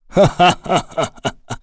Dakaris-Vox_Happy2.wav